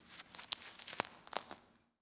Babushka / audio / sfx / Farming / SFX_Harvesting_04_Reverb.wav
SFX_Harvesting_04_Reverb.wav